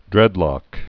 (drĕdlŏk)